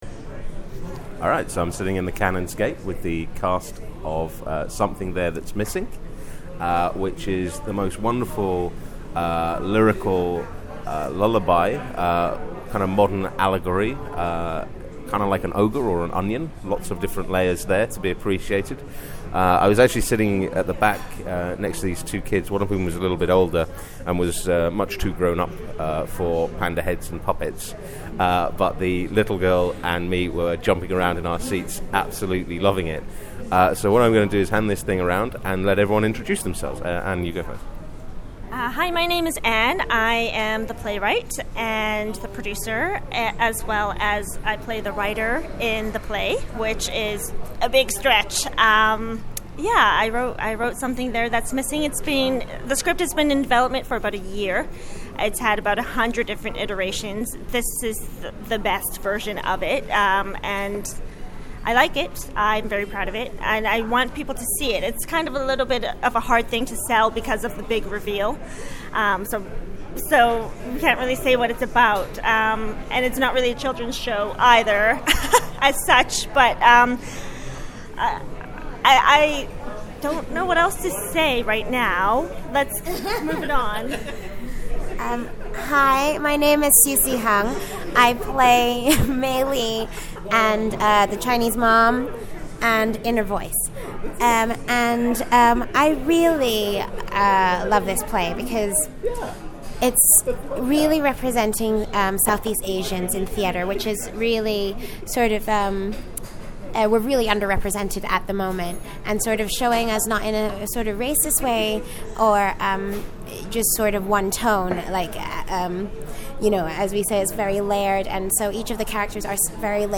listen-to-our-interview-with-something-there-thats-missing.mp3